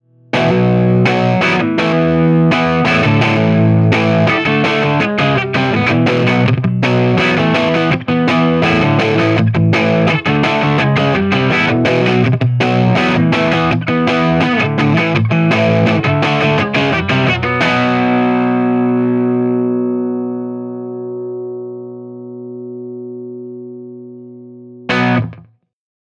18 Watt v6 - 6V6 Dirty Marshall G12T-75
Note: We recorded dirty 18W tones using both the EL84 and 6V6 output tubes.
Keep in mind when listening that the tones I dialed in were "average" tones.